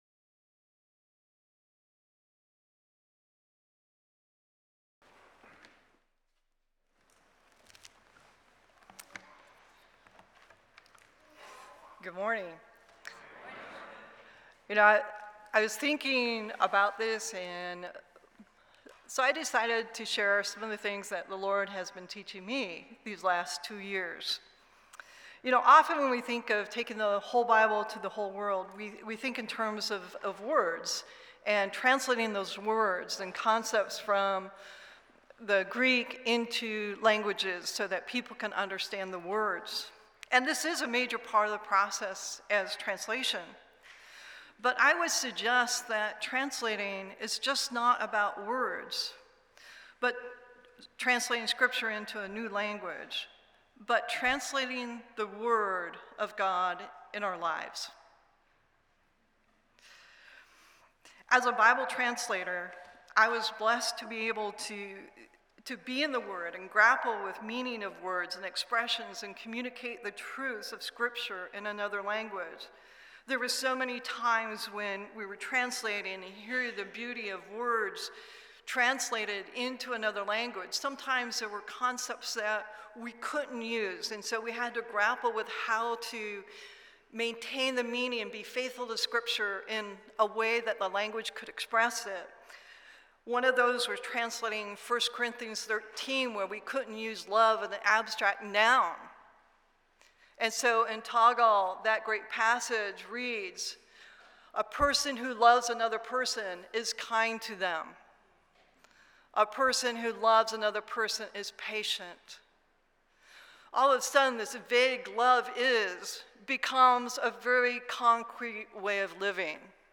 Chapel at Estes
Sermon